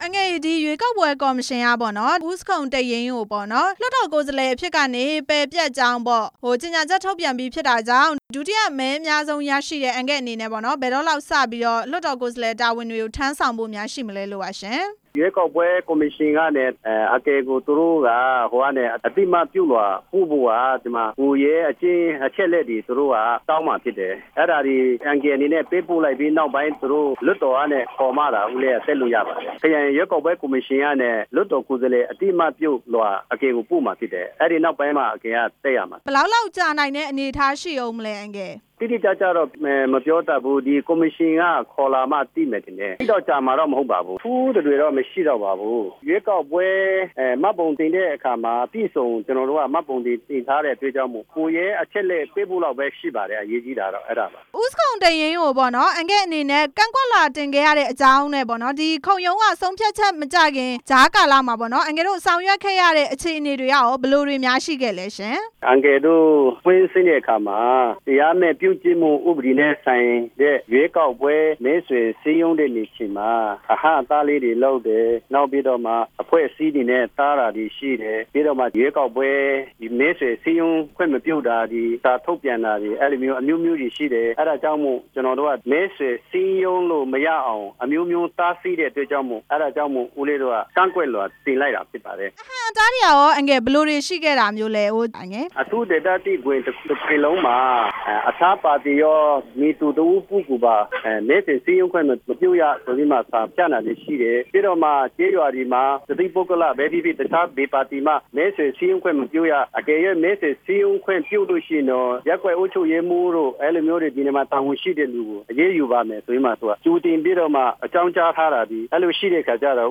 အမျိုးသားလွှတ်တော် ကိုယ်စားလှယ် ဦးယောနာ နဲ့ မေးမြန်းချက်